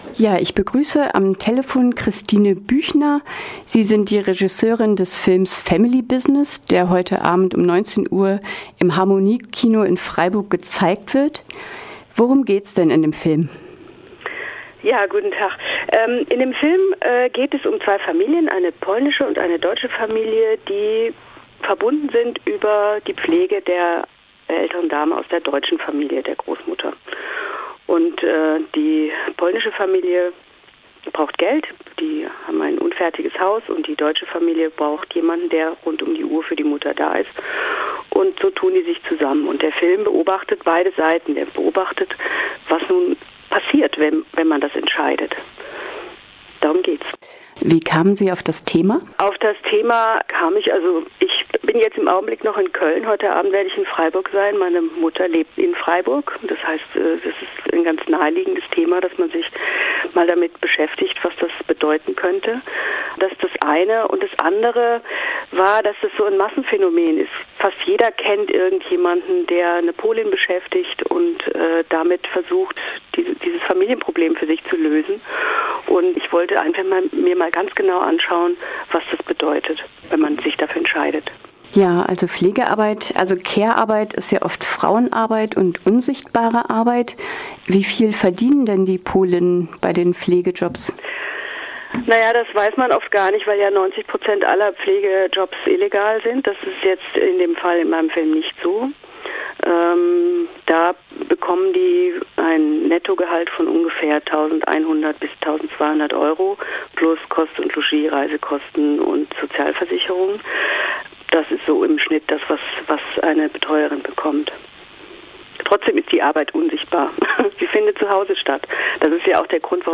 Wir sprachen mit der Regisseurin über ihren Film , es geht um Pflegearbeit. Was war ihre Motivation und wie war es so nah in die Familien hineinzugehen?